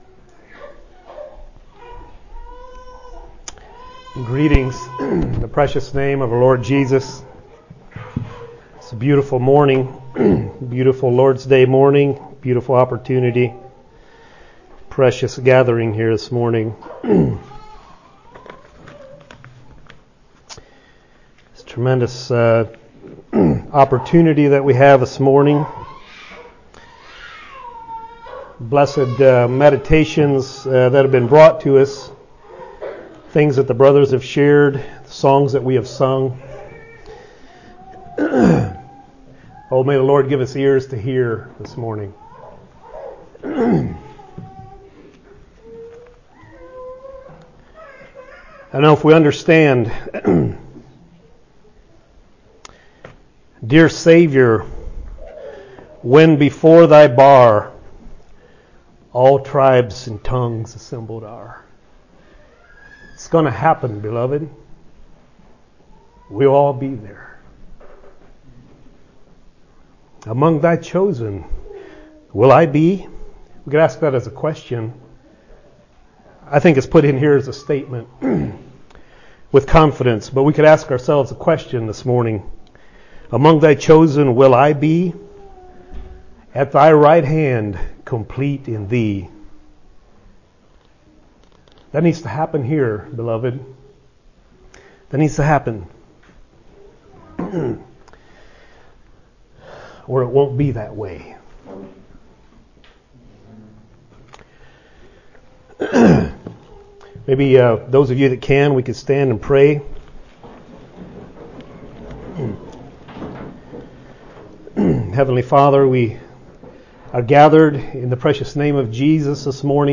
Sermons | Be Strengthened and Encouraged by God's Word
Listen to and download sermons from Shelbyville Christian Fellowship.